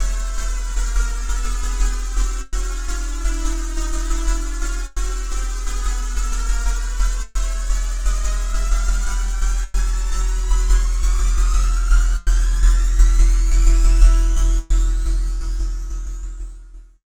58-PHASE  -L.wav